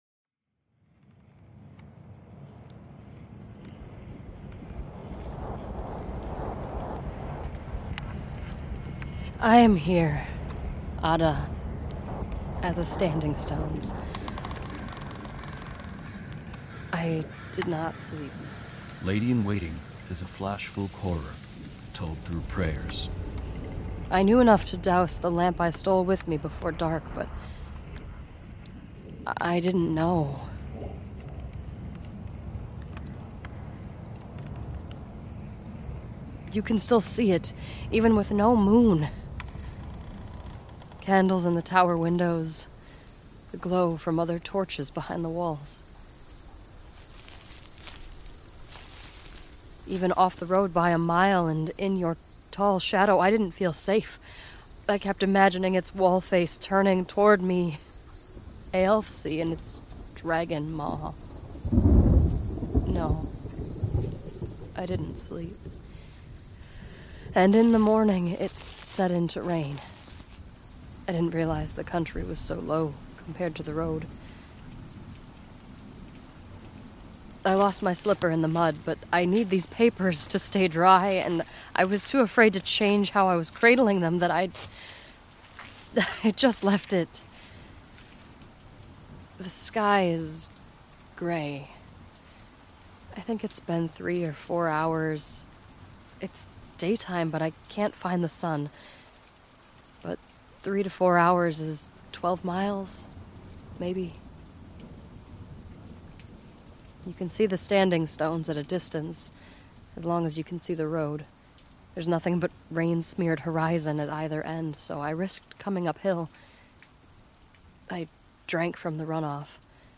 This is an immersive audio drama.